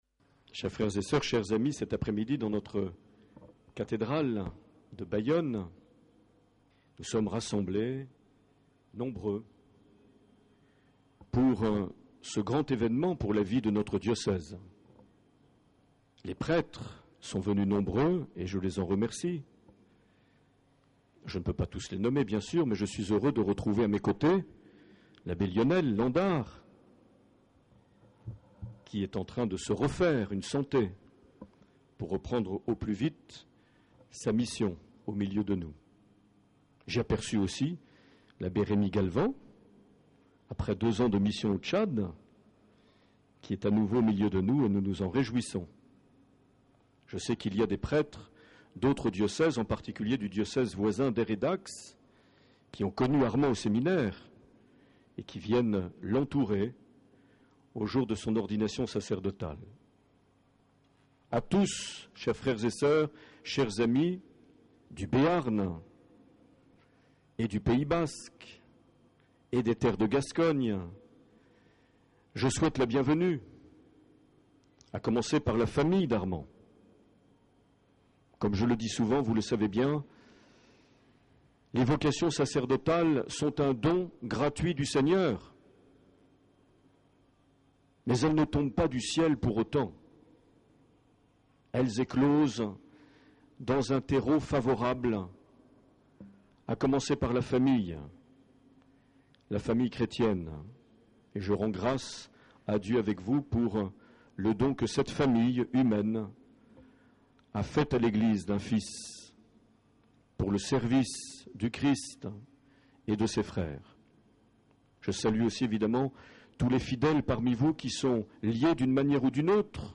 Cathédrale de Bayonne
Accueil \ Emissions \ Vie de l’Eglise \ Evêque \ Les Homélies \ 26 juin 2011
Une émission présentée par Monseigneur Marc Aillet